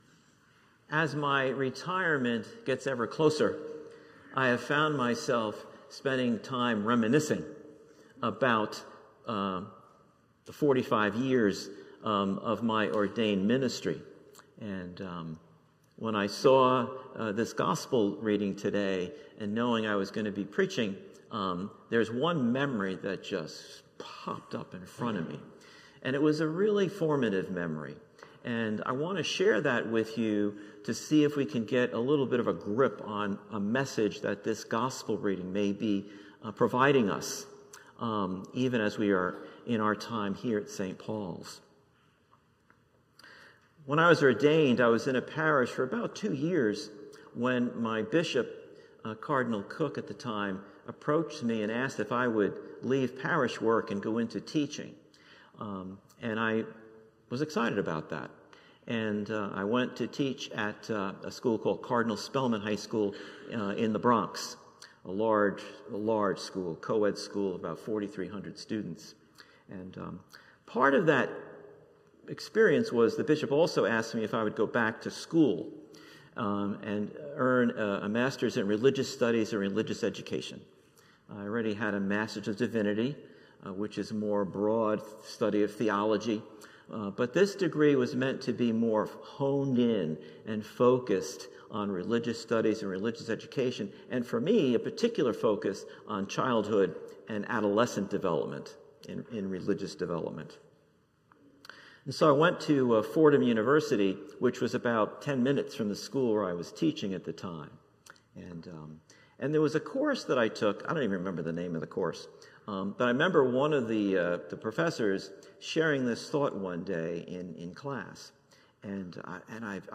St-Pauls-HEII-9a-Homily-22SEP24.mp3